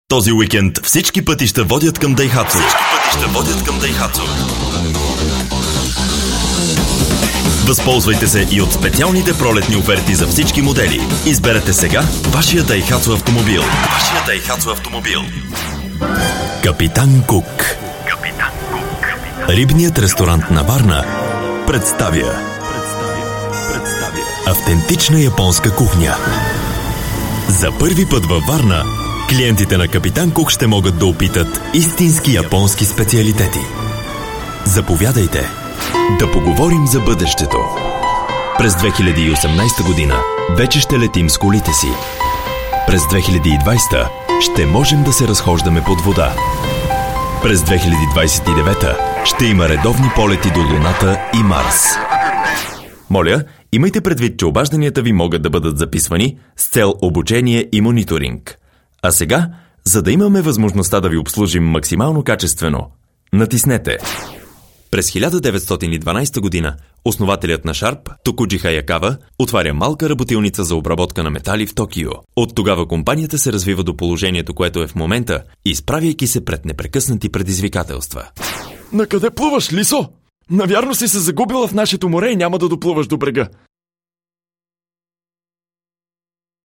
Dynamic, clean and deep voice.
Sprechprobe: eLearning (Muttersprache):